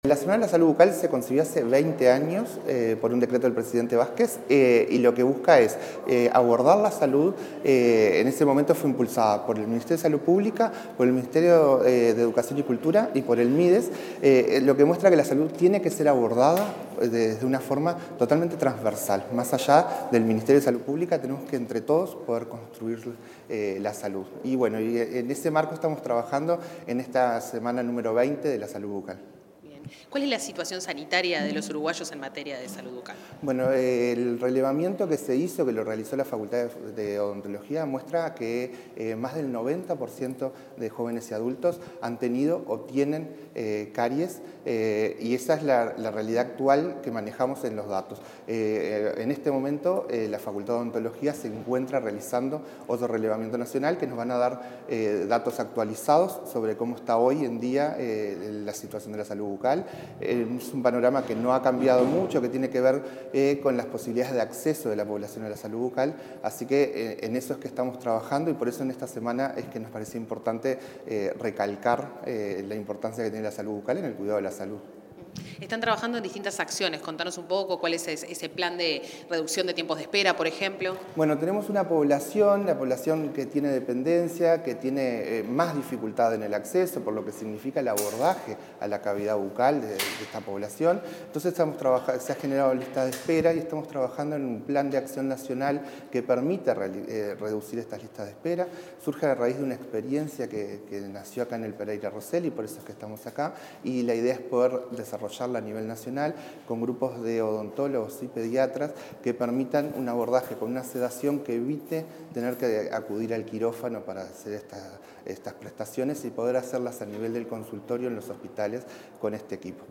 Declaraciones del director del Programa de Salud Bucal, Agustín Cataldo